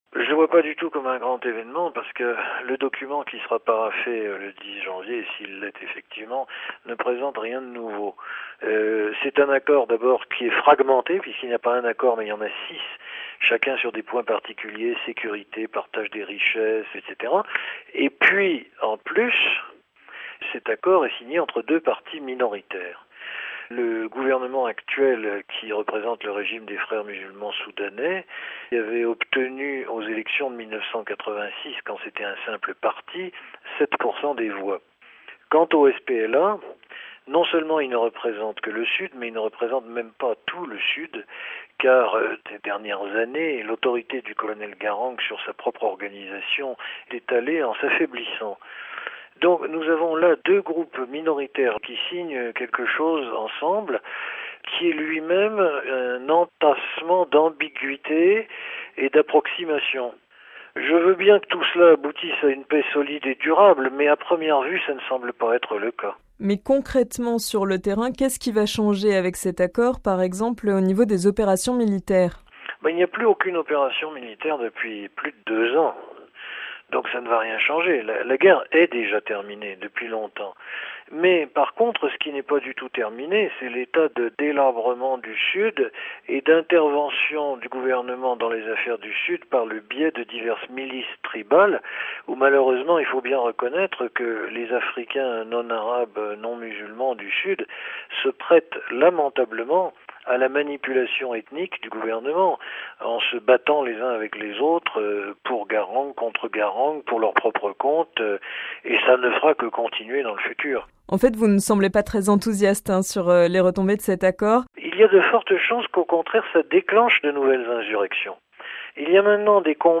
Dossier : accord de paix au sud Soudan